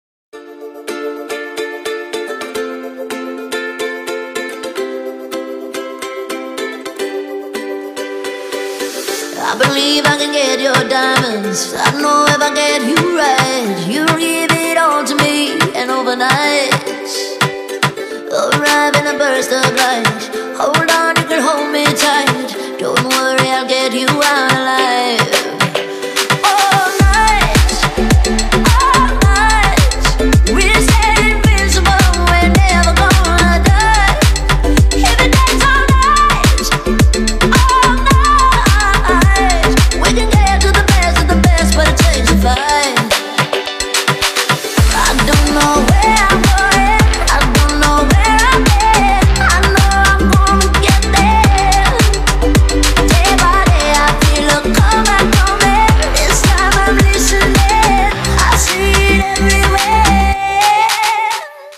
• Качество: 256, Stereo
громкие
женский вокал
заводные
dance
Electronic
EDM
электронная музыка
club
струнные
house